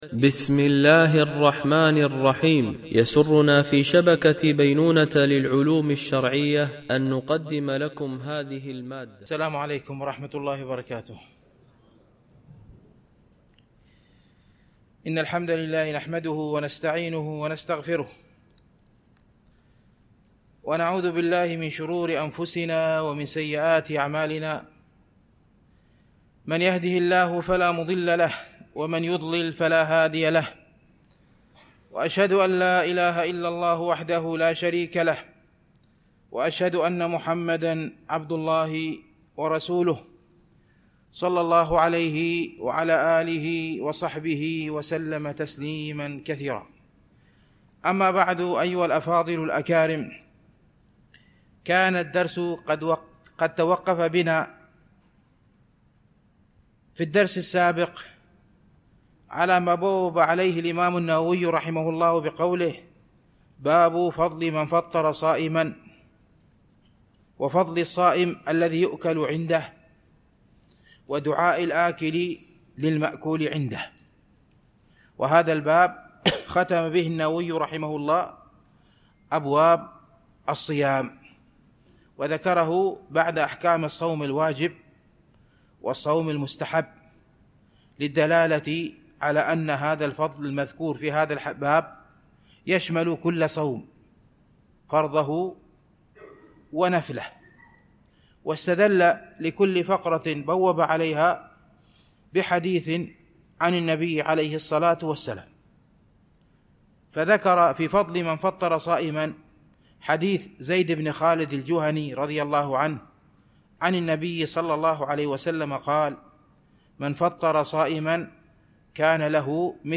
شرح رياض الصالحين – الدرس 335